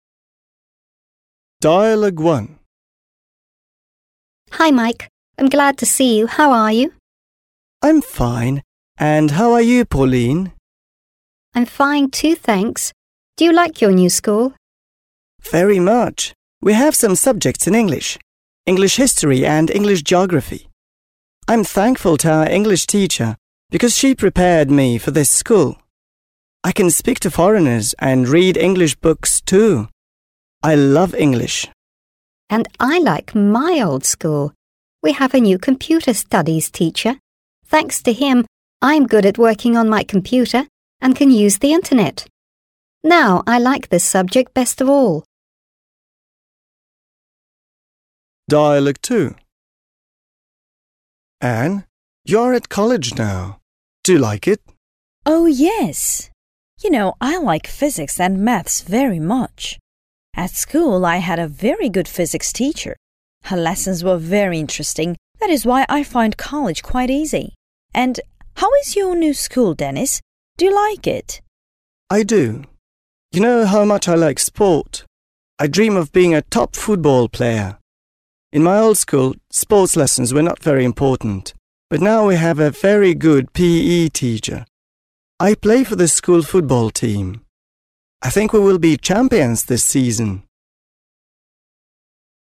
Диалог 1
Диалог 2